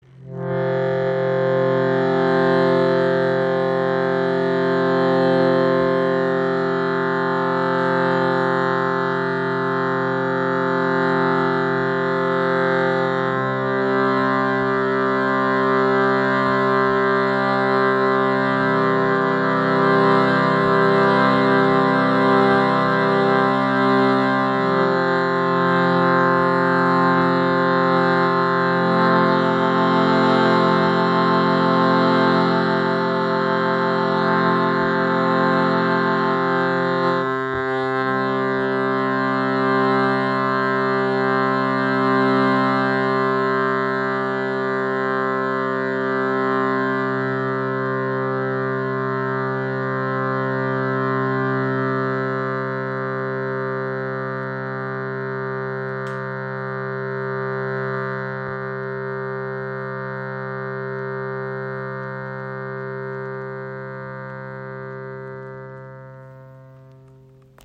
Shruti Box | Gross | C | Holzklappen | Transportschaden
• Icon Bordun-Instrument zur Begleitung von Mantragesang oder Obertongesang
Der Ton lässt sich sowohl laut und leise spielen und dabei gleichmässig halten.
• Tonumfang: C3-C4
• Stimmung: 440 Hz